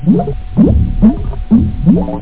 1 channel
sample0C.bubble.mp3